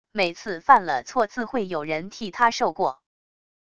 每次犯了错自会有人替他受过wav音频生成系统WAV Audio Player